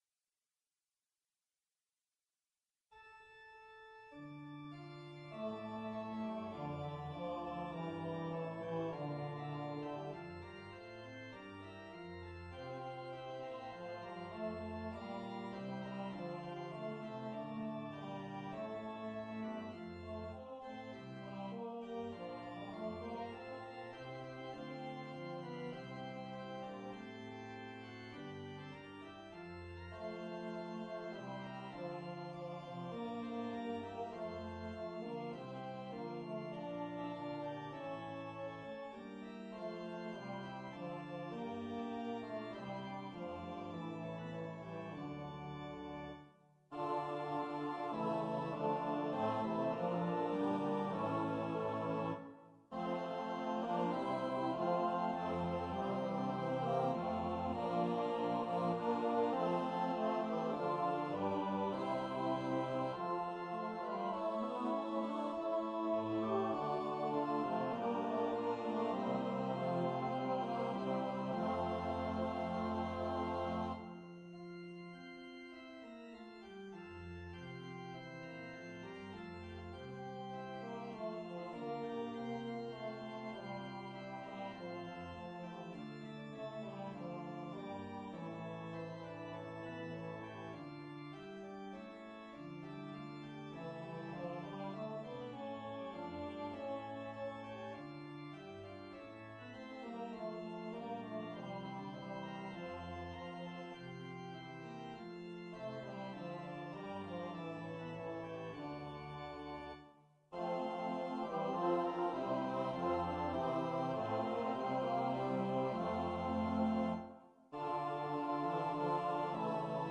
EARLY-SEVENTEENTH-CENTURY ENGLISH SACRED MUSIC
Voices:SSAABB soli, SSAATB chorus & organ